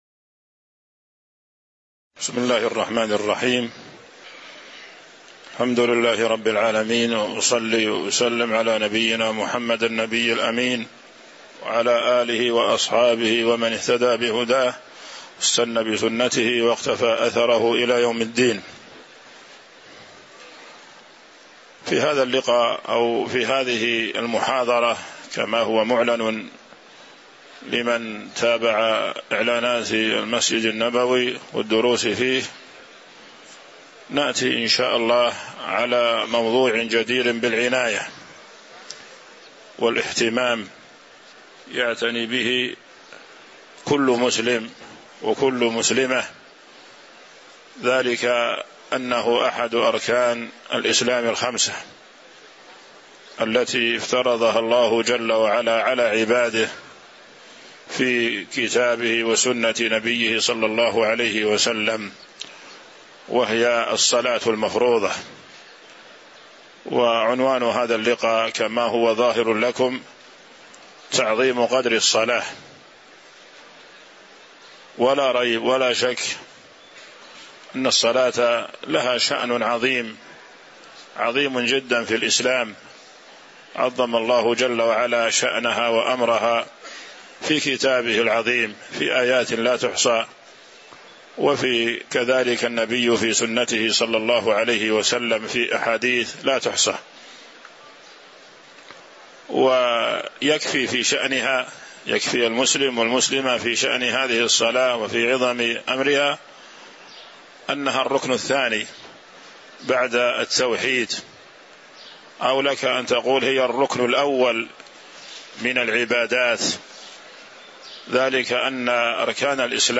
تاريخ النشر ١٠ ربيع الأول ١٤٤٦ هـ المكان: المسجد النبوي الشيخ